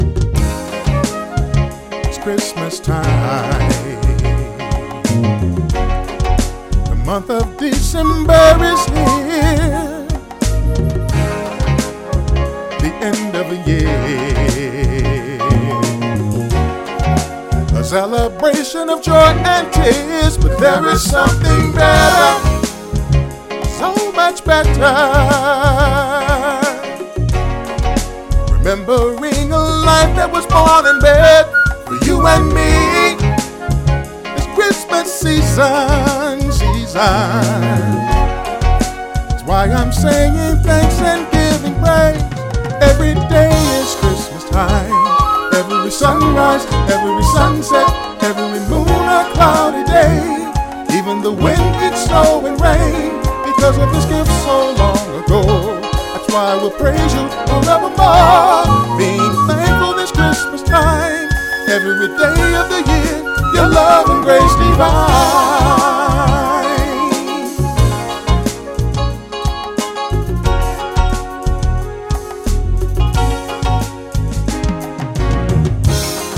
holiday single
flutist
Mastered and produced in Denver Colorado.